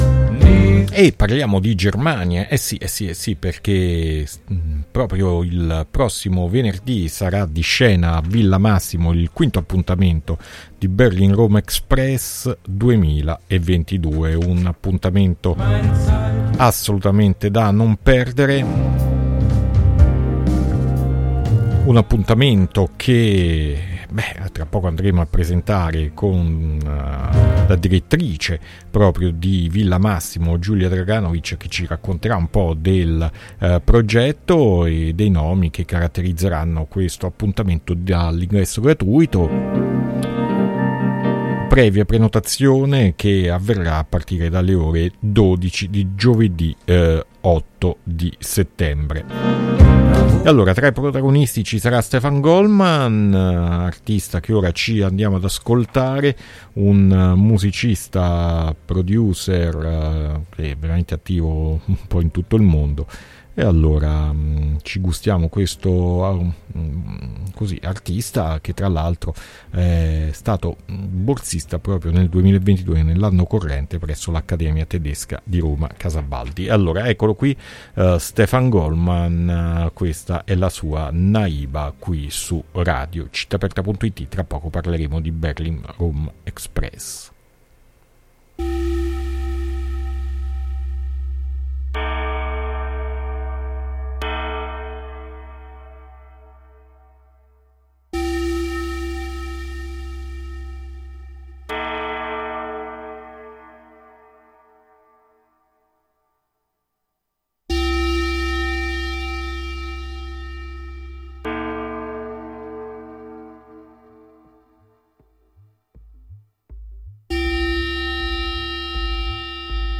Intervista BERLIN ROM EXPRESS 2022 a Puzzle 5-9-2022